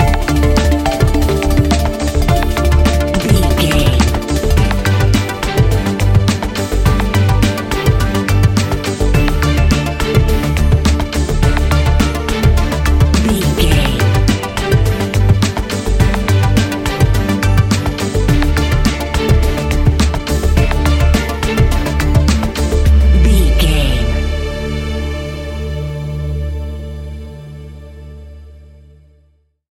Ionian/Major
D♭
electronic
techno
trance
synths
synthwave